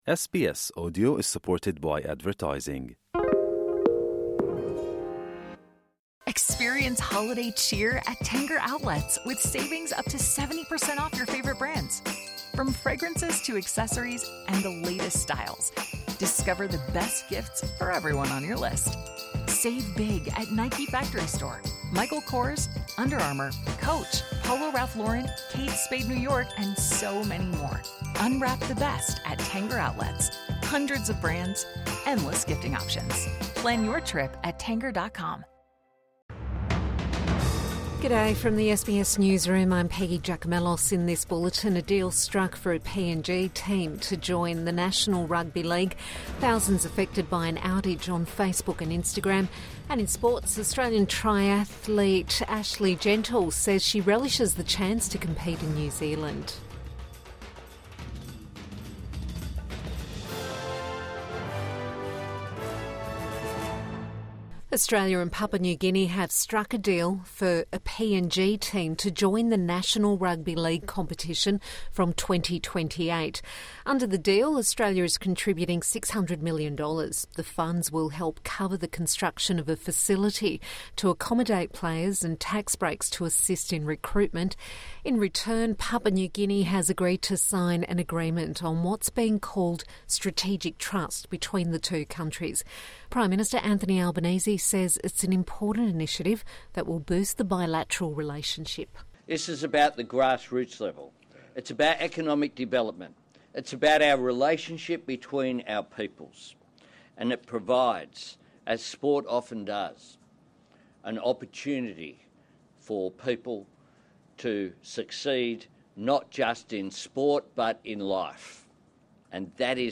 Midday News Bulletin 12 December 2024